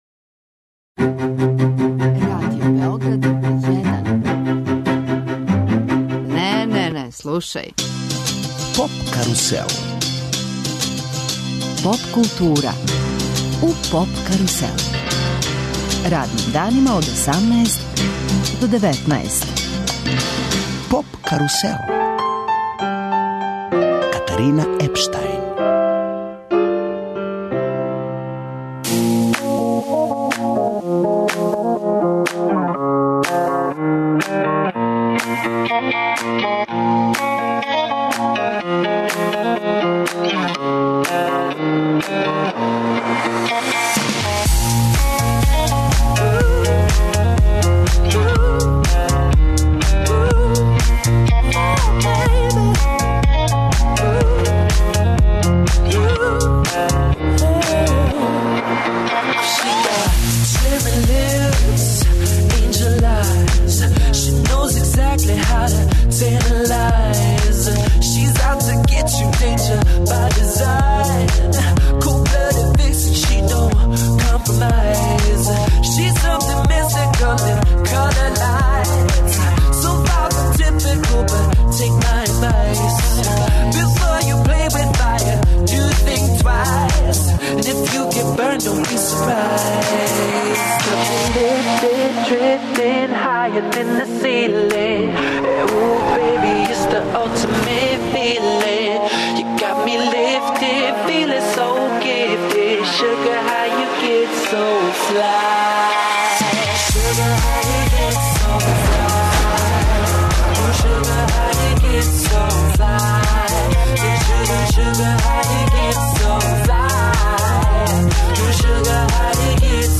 Радио Београд 1 и ове године уживо реализује емисије са фестивала Eurosonic, из Холандије. Eurosonic Noorderslag је музички фестивал посвећен изградњи европске поп сцене.